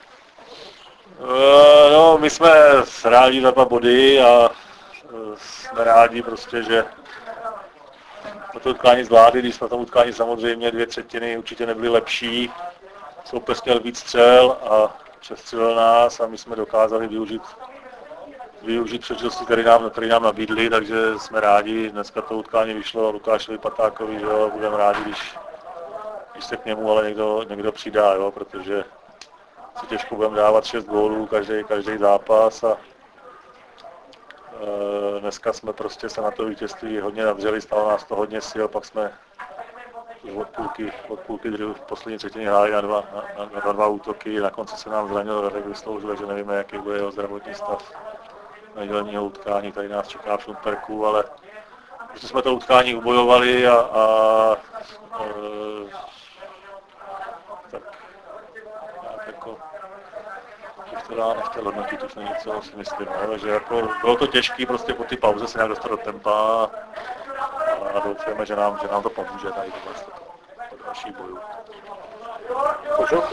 po utkání JUN TRE - VSE 6:4